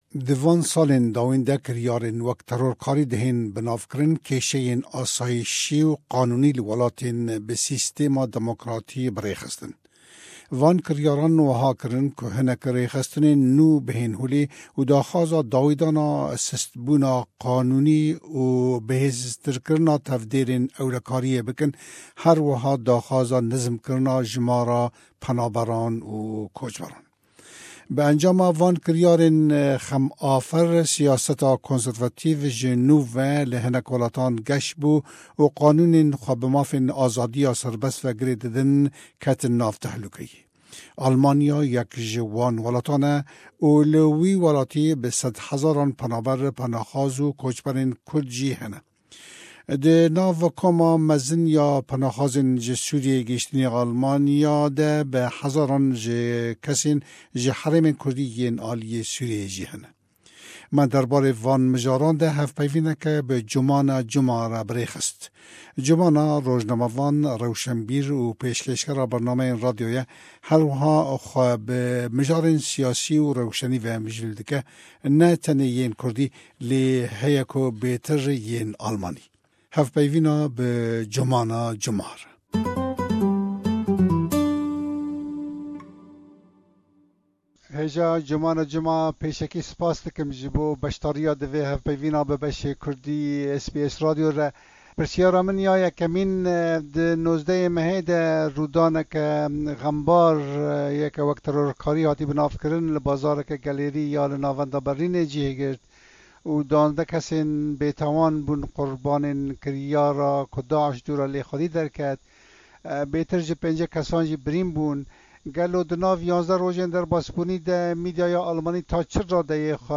Almanya yek ji wan welatan e û di nav koma mezin ya penaxwazên ji Sûriyê gihîshtinî Almanya de bi hezaran kesên ji herêmên kurdî yên aliyê Sûriyê jî hene. Derbarê van mijaran de me hevpeyvînek bi rojnamevan